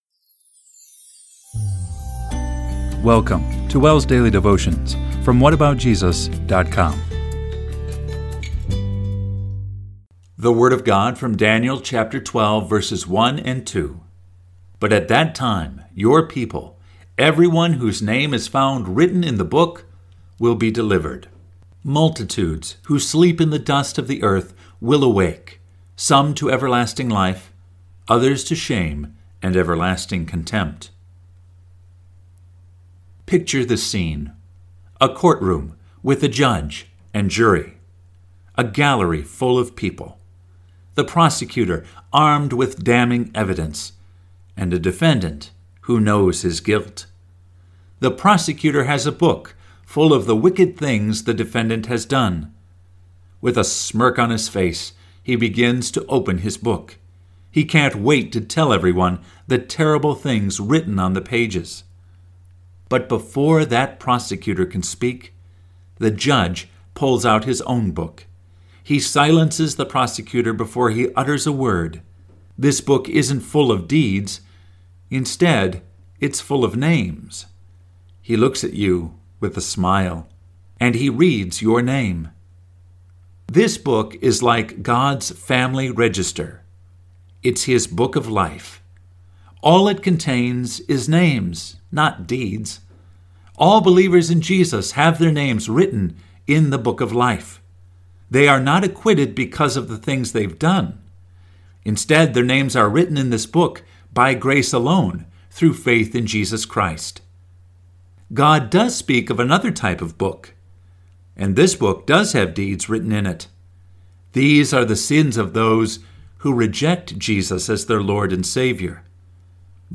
Daily Devotion